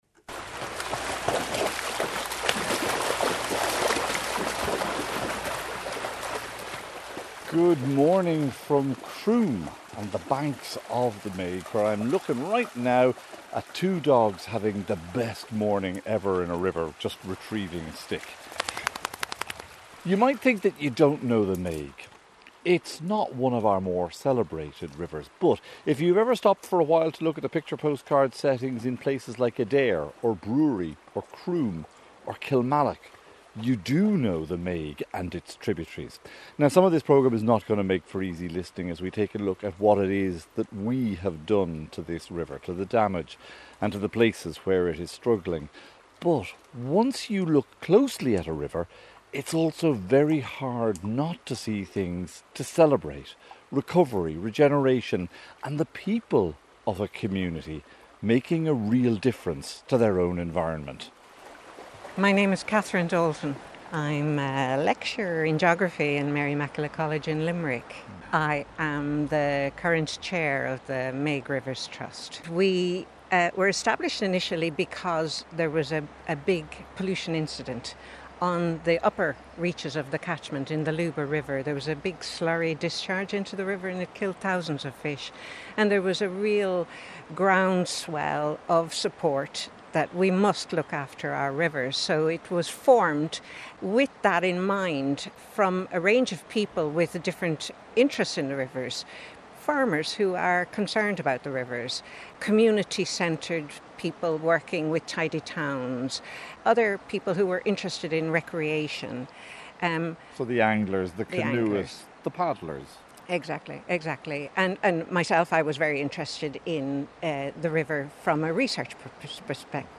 1 Gender Backlash and the Erosion of Everyday Rights 23:11 Play Pause 1d ago 23:11 Play Pause Play later Play later Lists Like Liked 23:11 We explore how gender backlash deepens the marginalisation of sex workers in Bangladesh, affecting their access to health, justice, and basic rights. Featuring the voice of a sex worker and insights from gender justice advocates, we examine how shrinking civic space and growing stigma are putting lives at risk—and what can be done to resist.